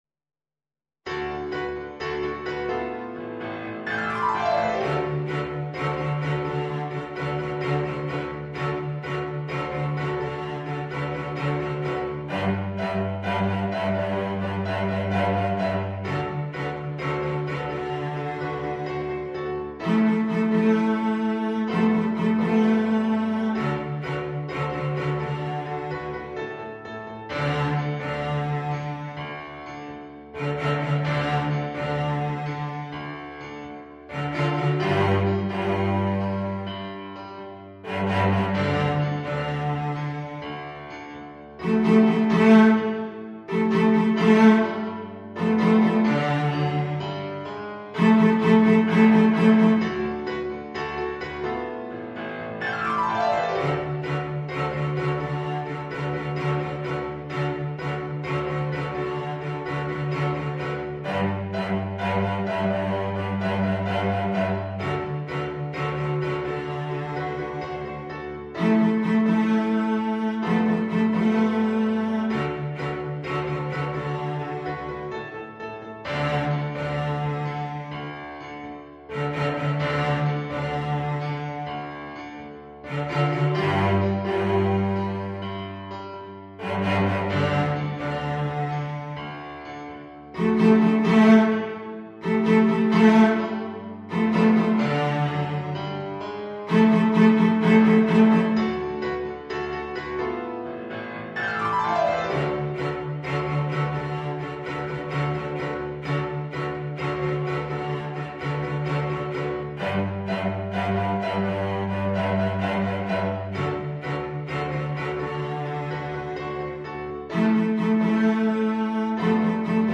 Ten easy ‘high-voltage’ pieces for double bass and piano.
• Attractive original fun-based melodies.